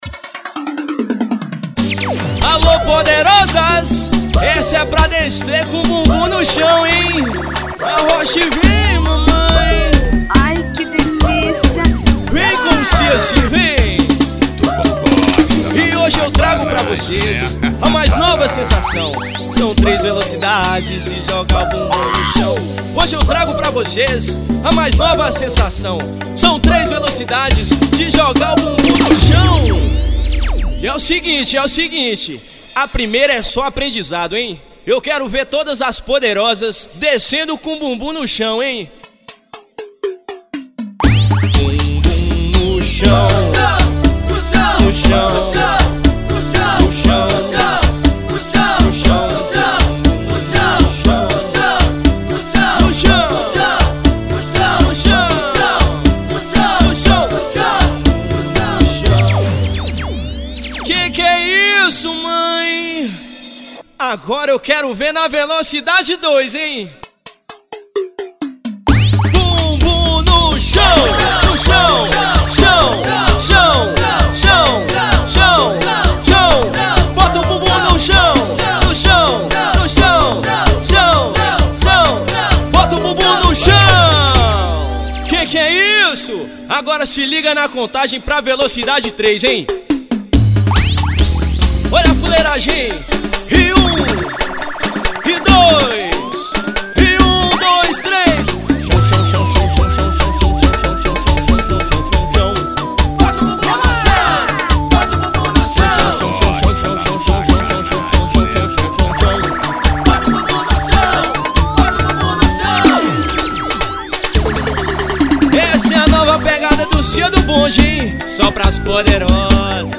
(ao VIVO).